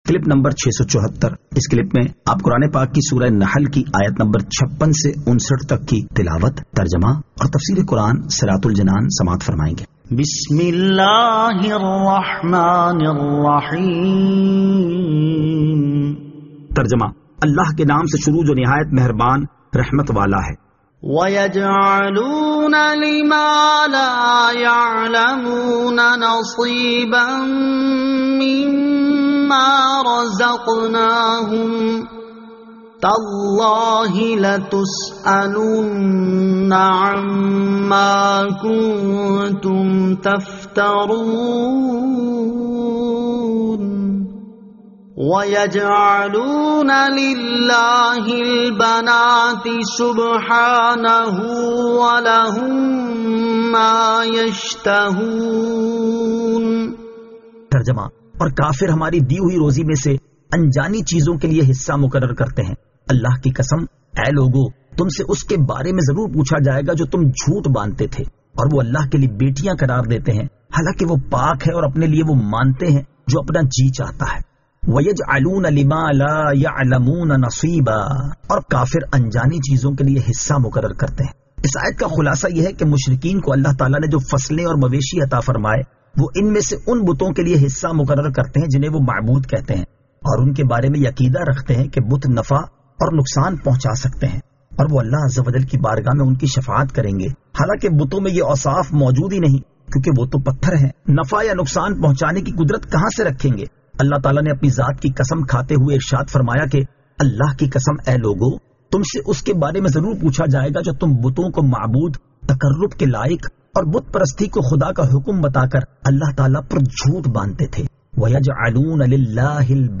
Surah An-Nahl Ayat 56 To 59 Tilawat , Tarjama , Tafseer